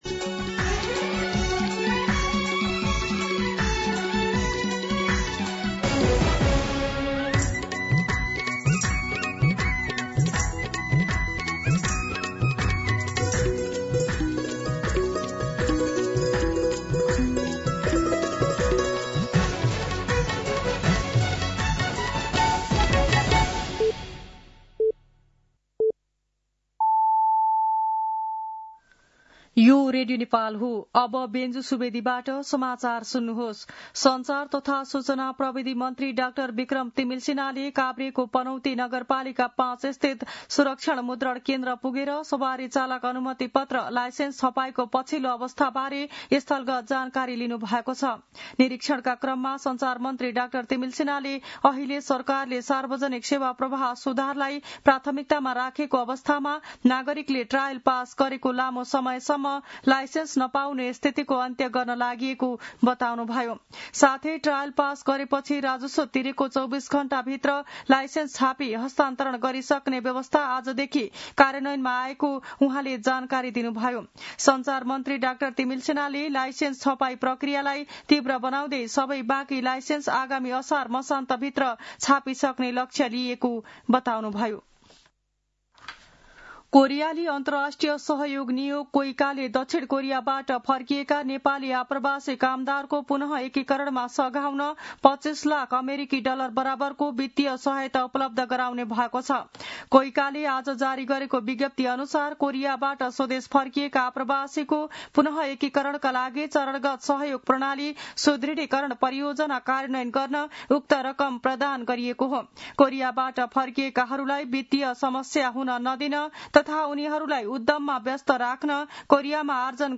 मध्यान्ह १२ बजेको नेपाली समाचार : ४ वैशाख , २०८३